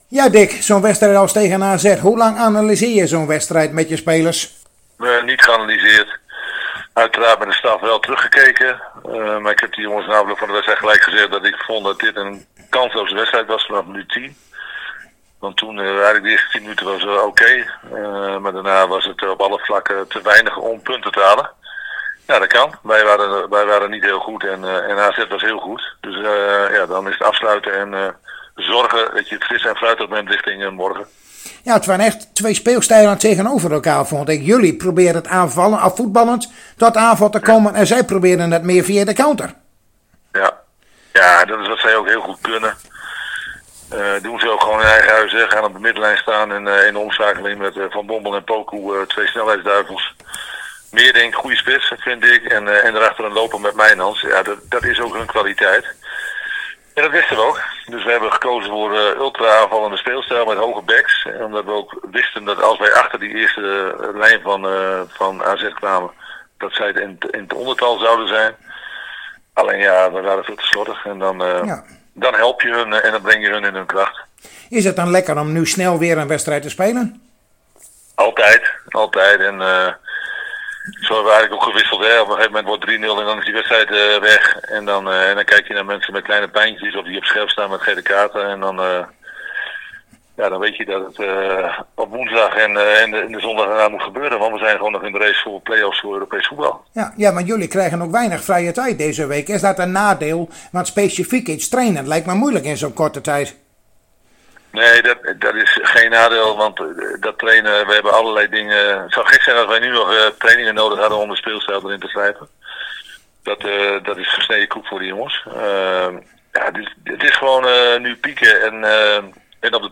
Zojuist spraken wij weer met Dick Lukkien over de wedstrijd van morgen tegen Ajax.